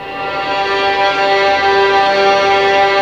Index of /90_sSampleCDs/Roland LCDP13 String Sections/STR_Violins FX/STR_Vls Pont wh%